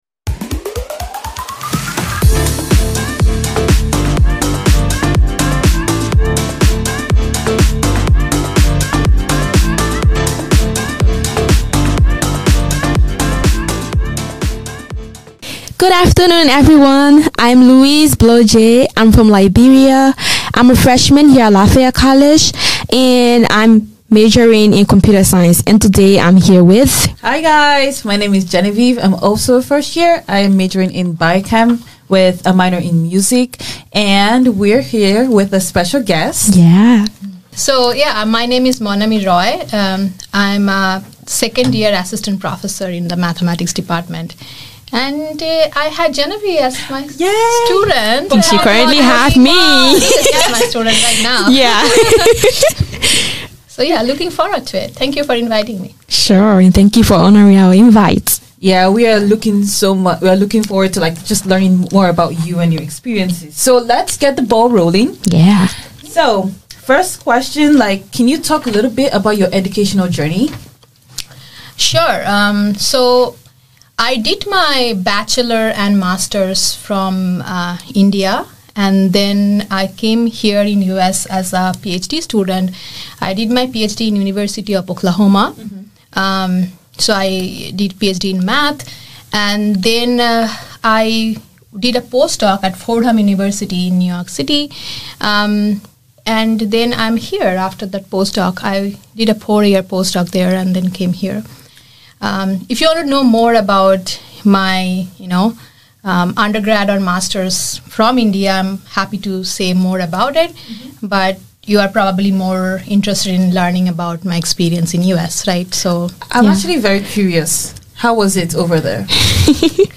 Student Interviewers: